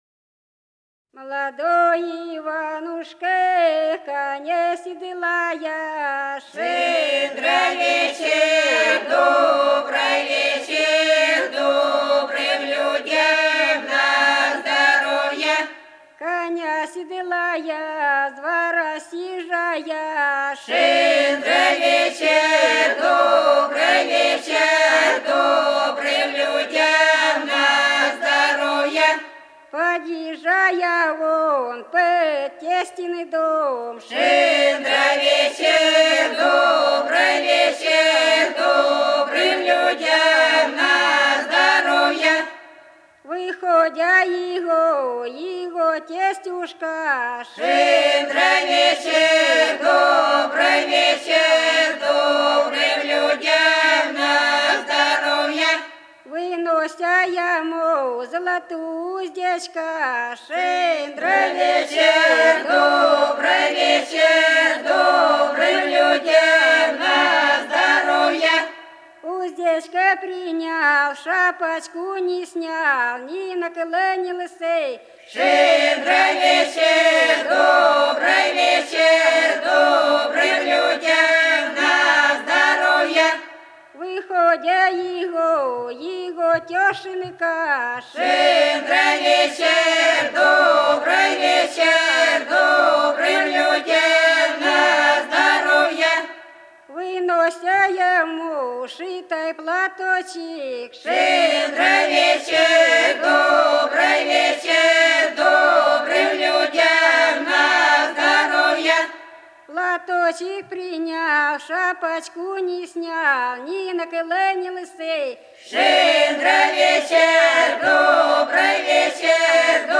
Голоса уходящего века (село Фощеватово) Молодой Иванушка коня седлая (щедровка)